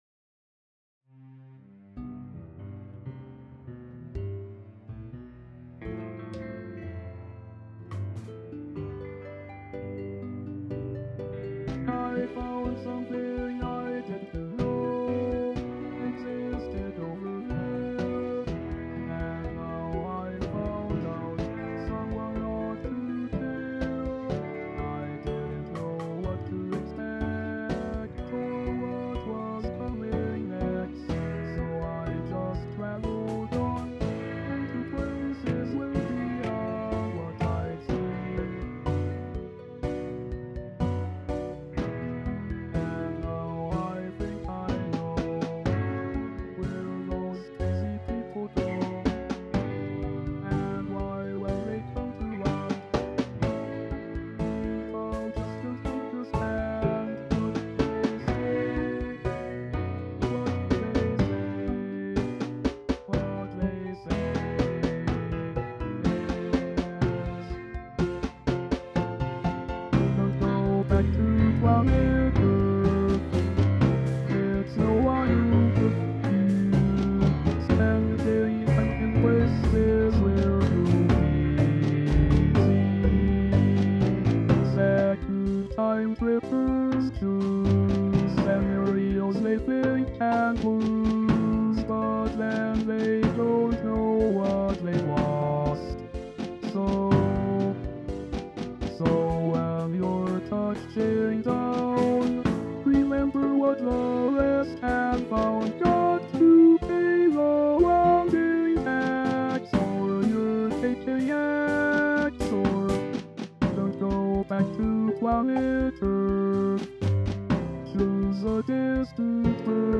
Synth vocal arrangement